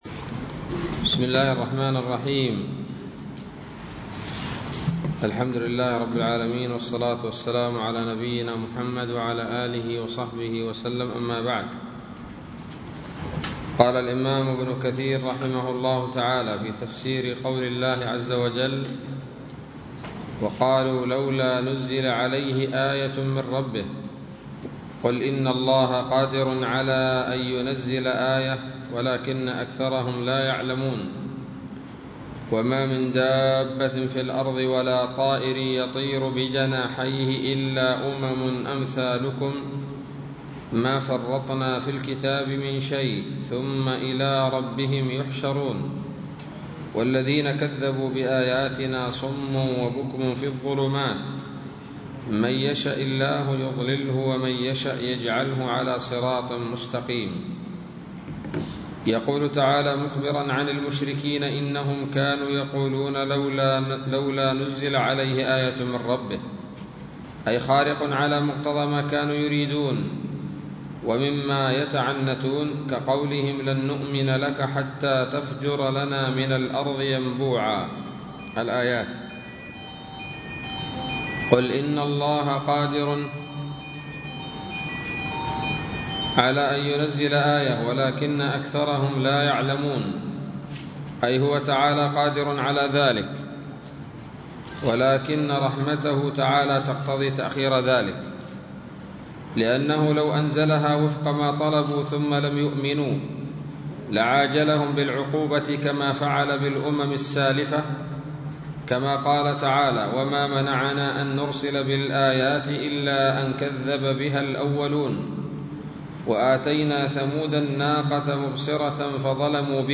الدرس الثامن من سورة الأنعام من تفسير ابن كثير رحمه الله تعالى